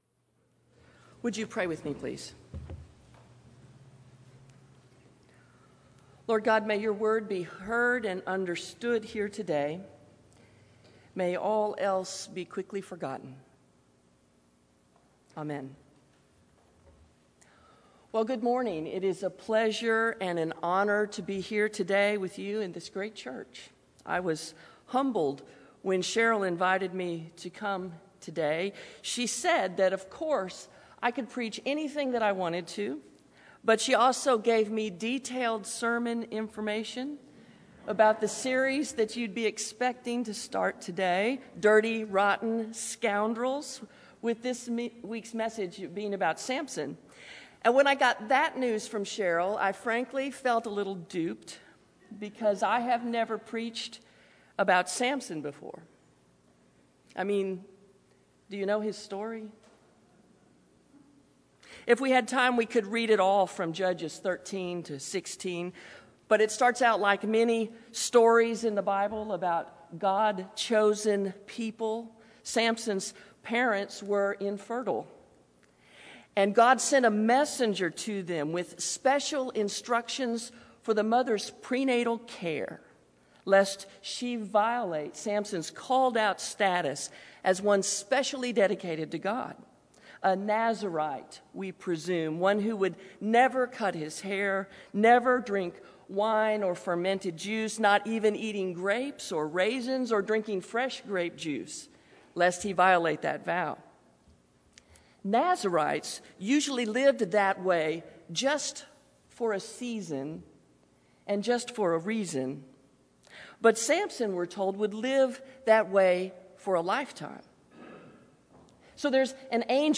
Aldersgate United Methodist Church Sermons